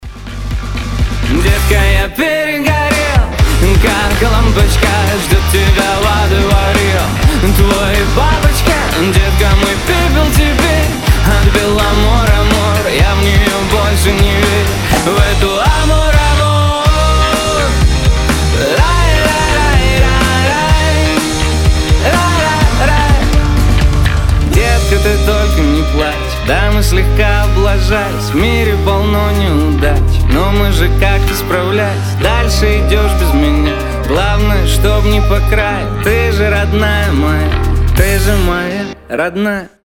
• Качество: 320, Stereo
мужской голос
Pop Rock
бодрые